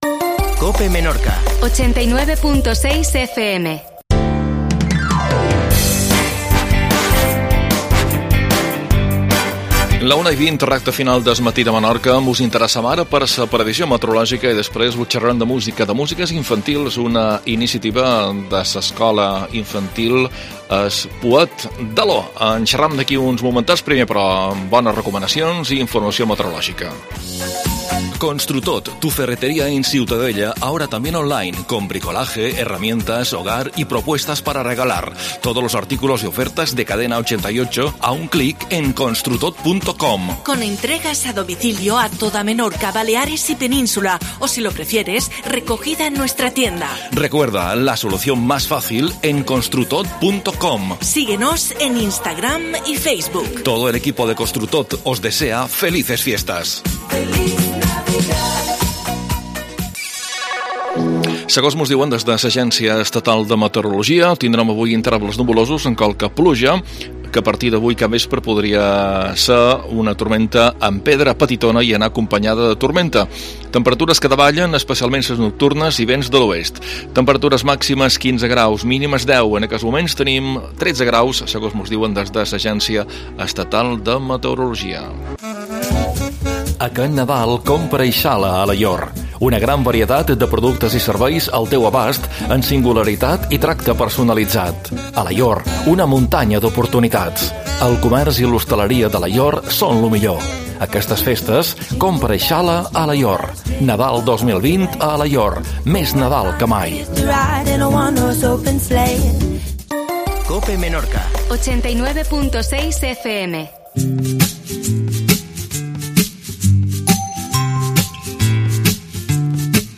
AUDIO: Previsio meteorologica. Cançons infantils EI es Pouet d'Alaior, "Aixi ho feim"